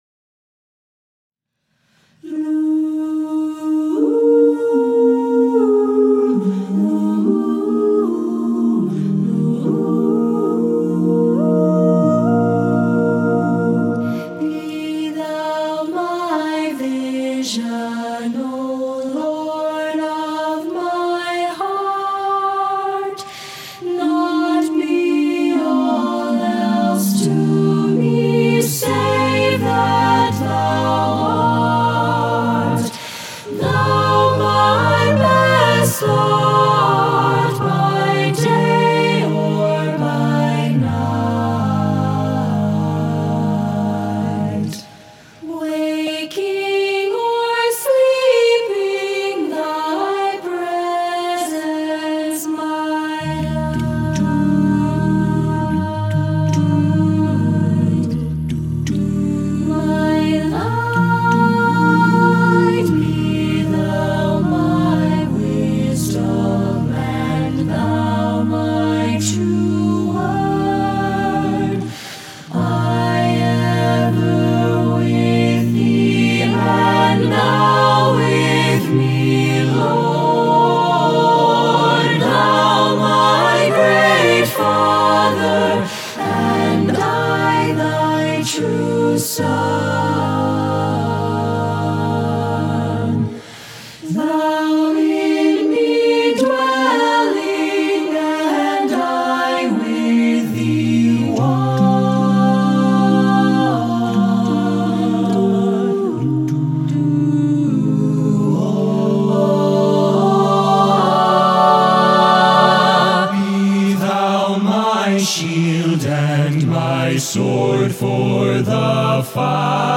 Choral Church Concert/General Multicultural
Irish Hymn
SATB A Cap